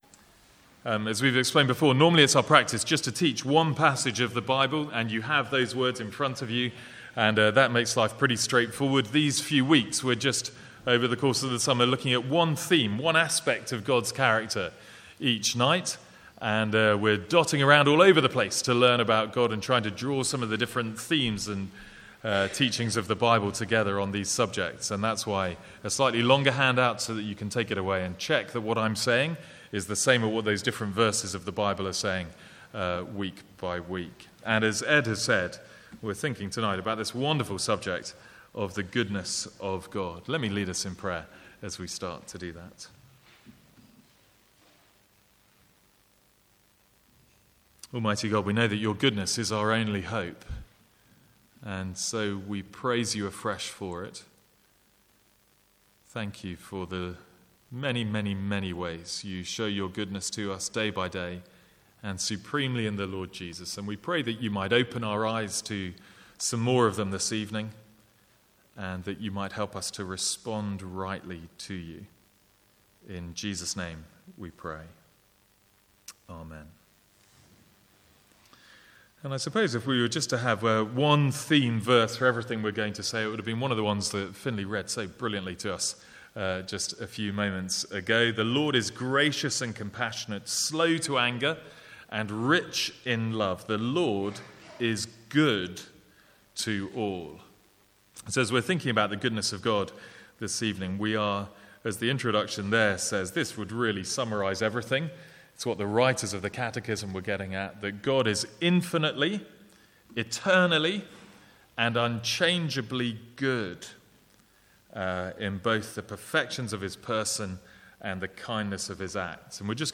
From the Sunday evening series on Question 4 of the Westminster Shorter Catechism - "What is God?"